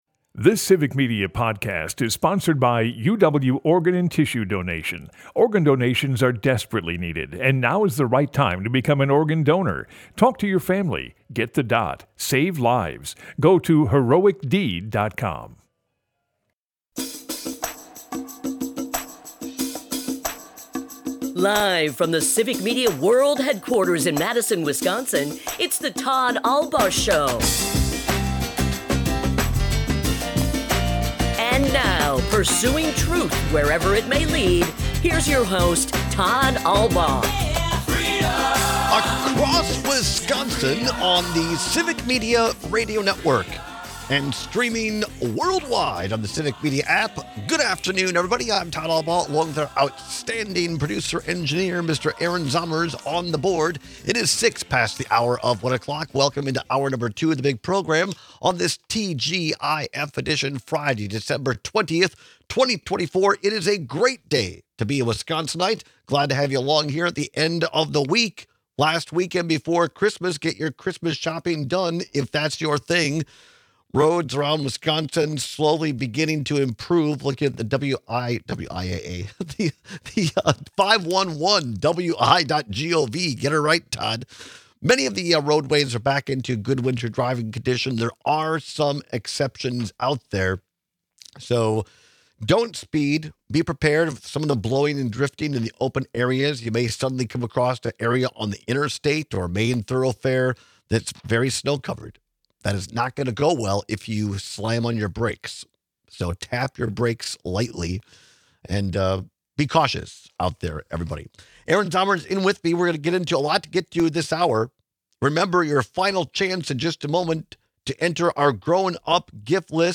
We take your calls and texts with opinions!